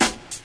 • '00s Hip-Hop Steel Snare Drum D Key 02.wav
Royality free snare sample tuned to the D note. Loudest frequency: 2726Hz
00s-hip-hop-steel-snare-drum-d-key-02-AfG.wav